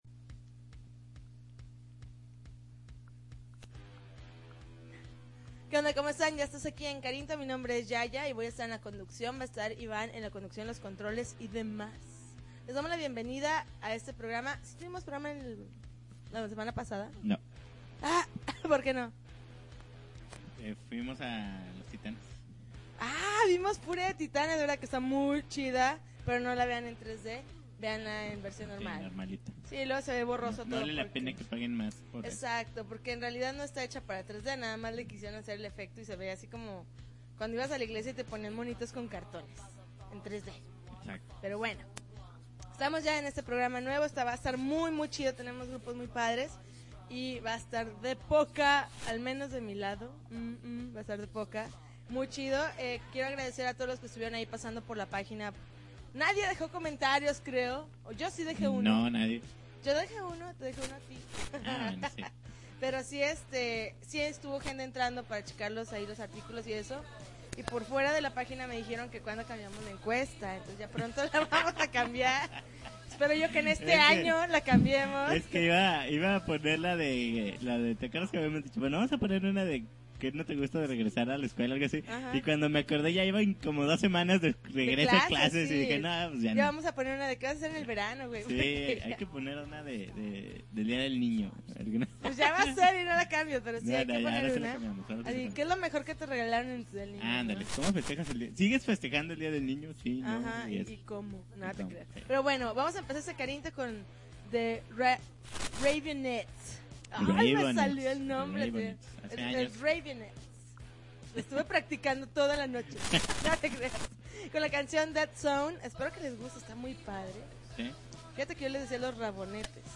Transmitiendo en vivo desde el cuartel general de Carinto
April 25, 2010Podcast, Punk Rock Alternativo